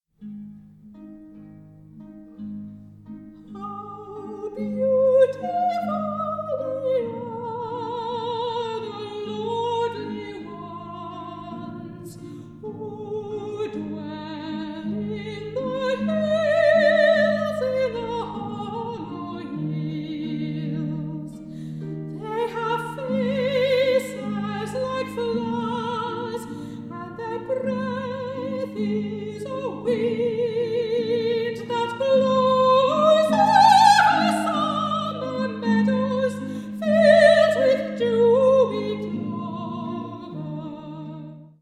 soprano
harp
violin